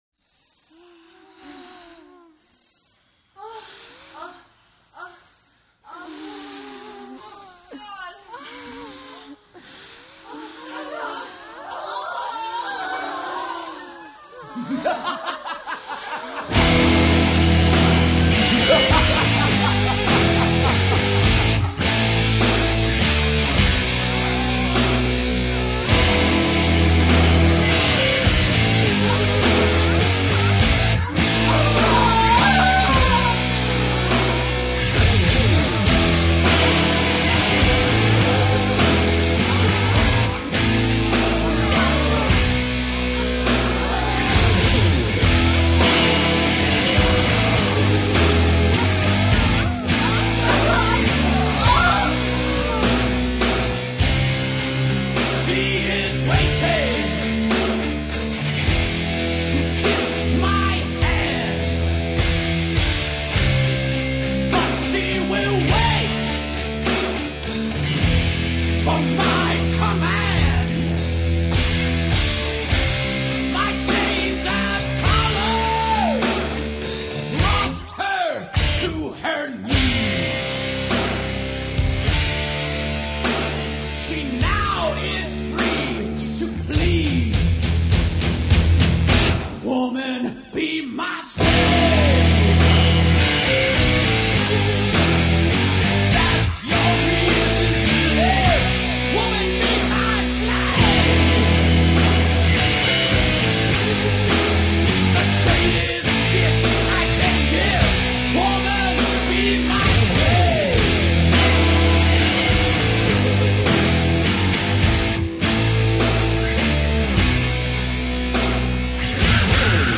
اولش ی سری صداهای ناجور داره
genre: metal/rock